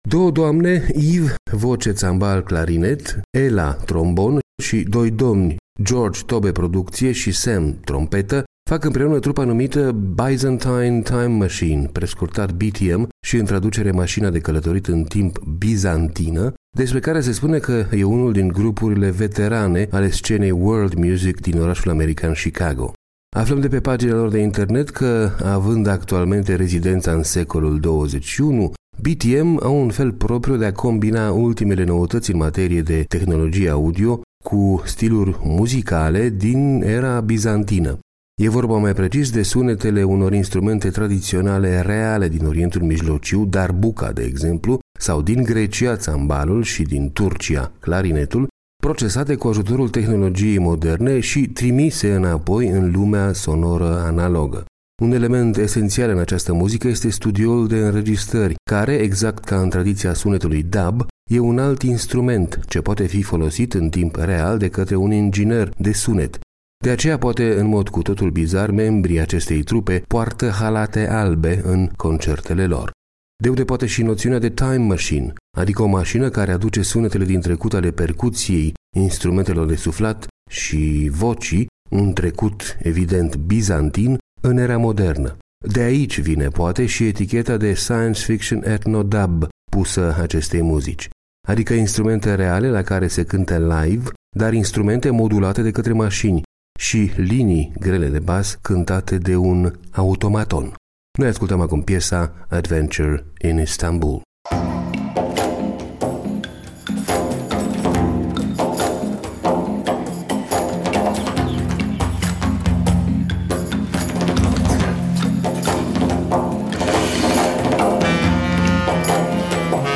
Science-fiction ethno dub.